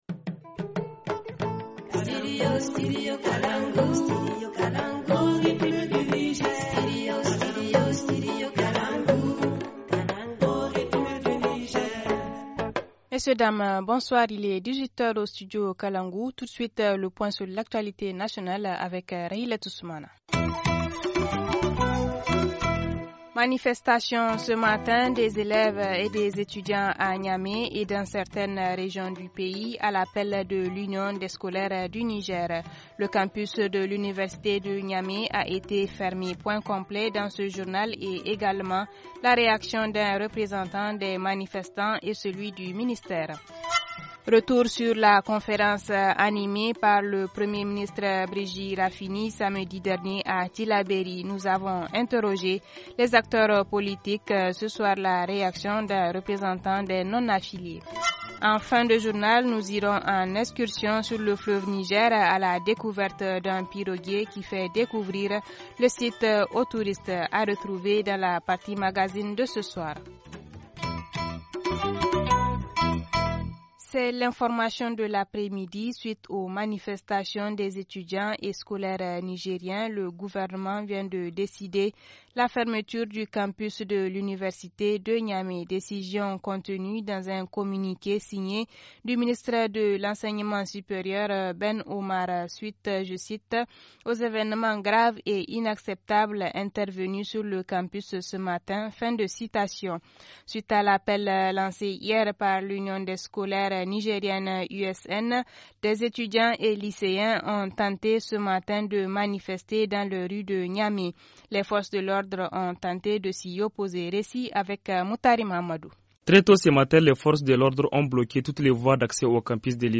Point complet dans ce journal et également la réaction d’un représentant des manifestants et celui du ministère.